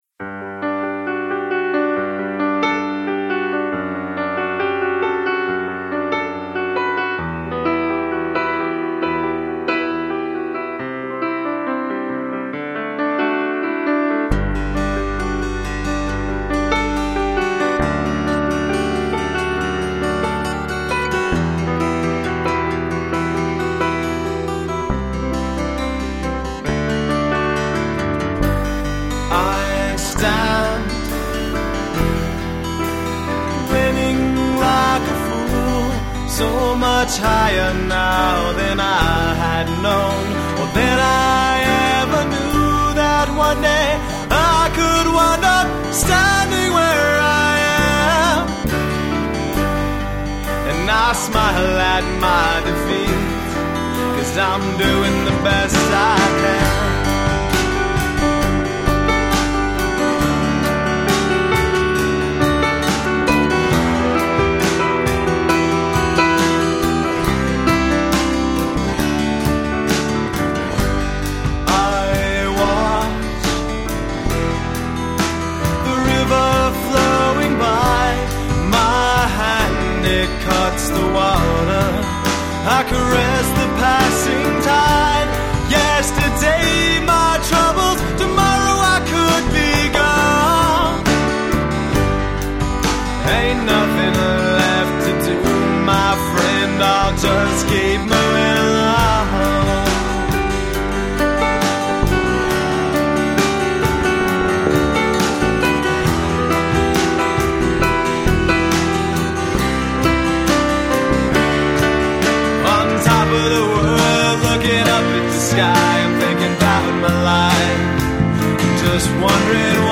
Guitar, Vocals
Drums, Percussion
Piano
Bass Guitar